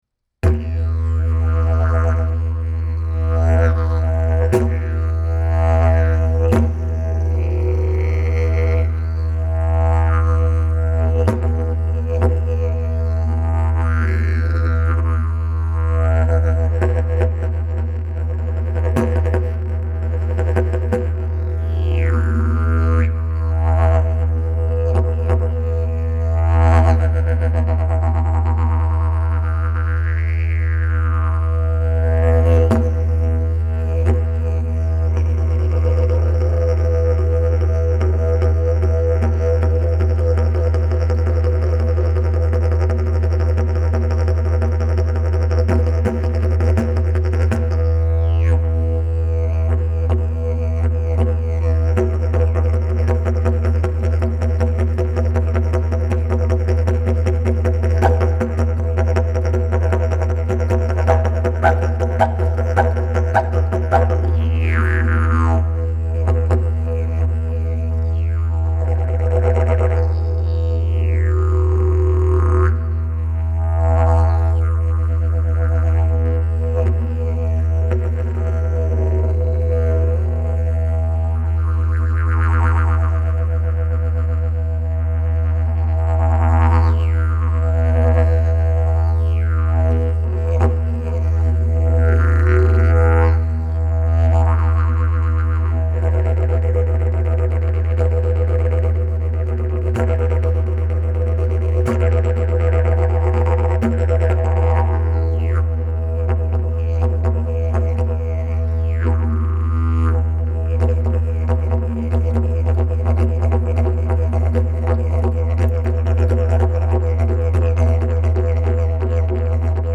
Key: E Length: 47" Bell: 3.5" Mouthpiece: Canary, Red Zebrawood Back pressure: Very strong Weight: 2.2 lbs Skill level: Any Sale pending
Didgeridoo #635 Key: E